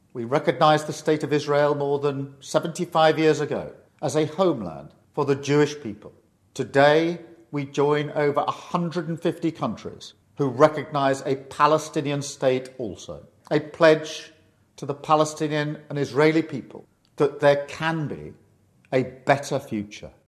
Prime Minister Starmer released a statement via X this afternoon: